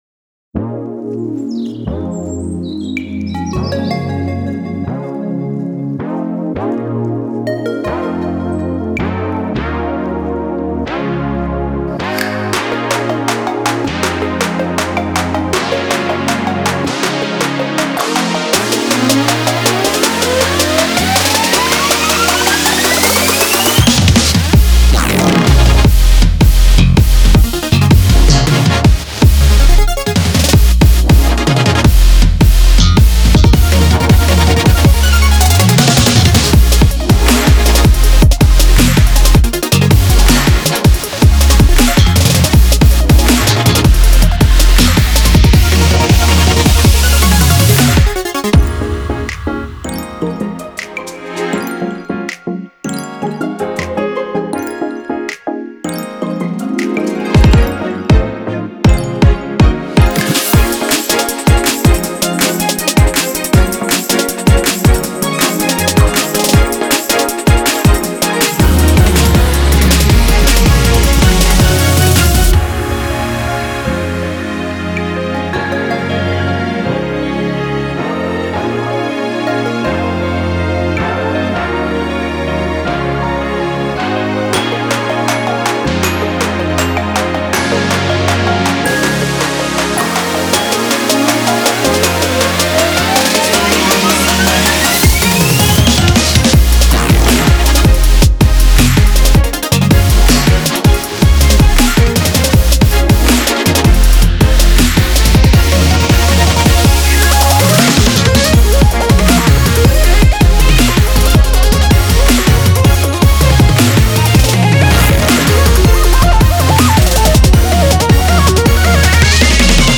BPM80-160
Audio QualityPerfect (High Quality)
brilliant future bass song